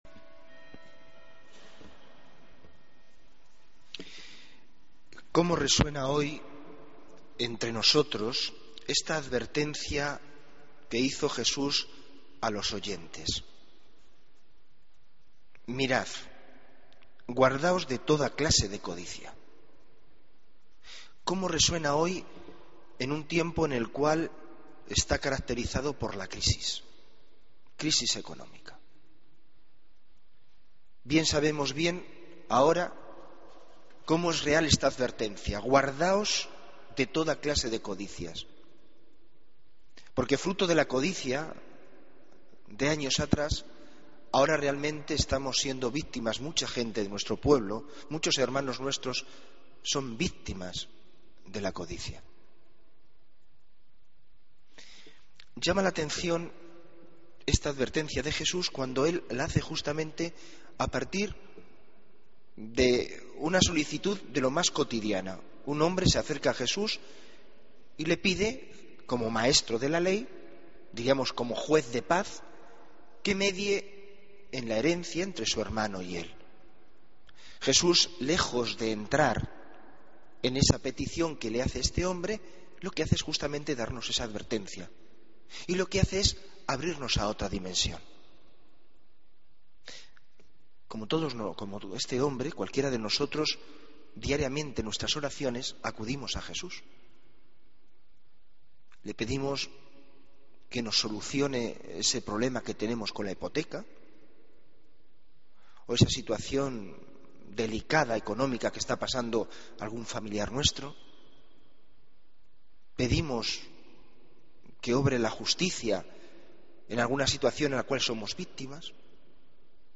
Homilía del 3 Agosto de 2014